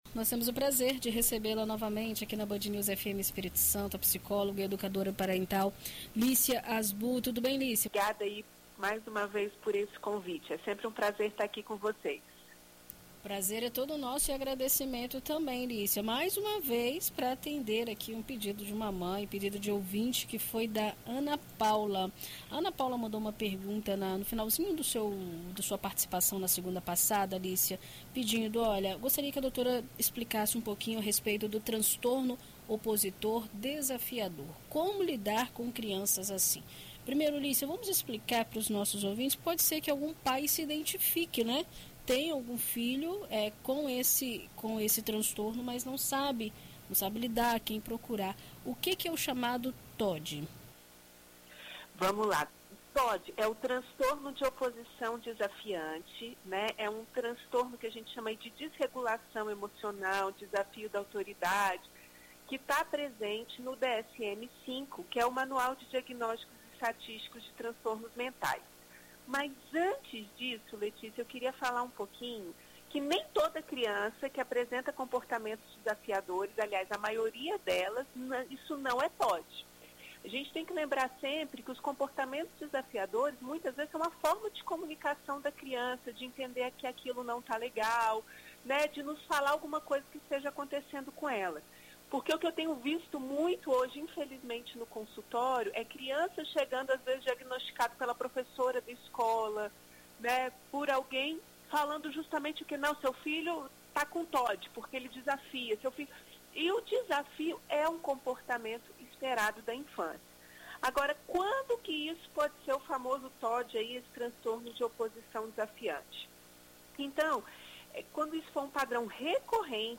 Em entrevista à BandNews FM ES nesta segunda-feira